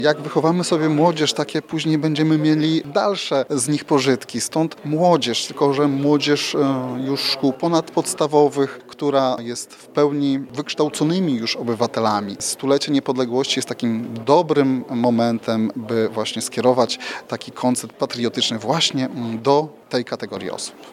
W wypełnionej po brzegi sali Ełckiego Centrum Kultury zasiadła przede wszystkim młodzież.
komendant.mp3